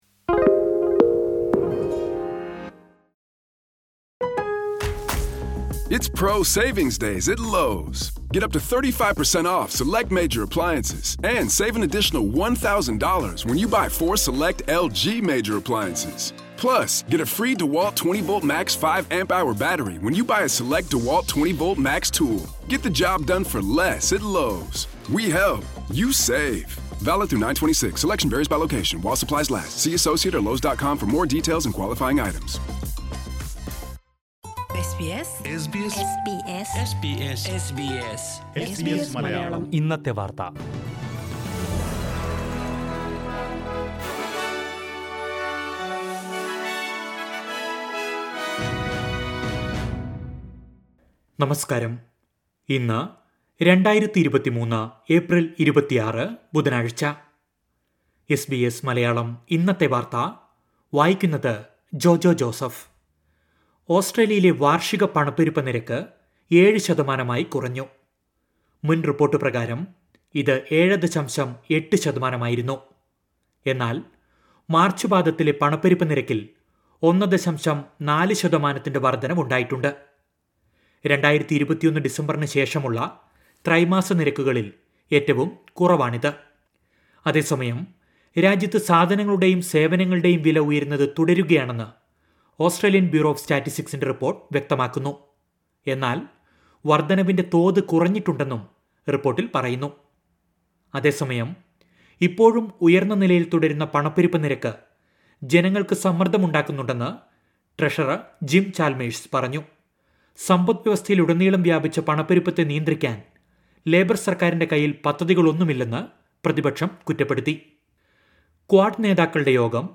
2023 ഏപ്രിൽ 26ലെ ഓസ്ട്രേലിയയിലെ ഏറ്റവും പ്രധാന വാർത്തകൾ കേൾക്കാം..